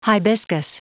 Prononciation du mot hibiscus en anglais (fichier audio)
Prononciation du mot : hibiscus